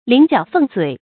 麟角凤嘴 lín jiǎo fèng zuǐ
麟角凤嘴发音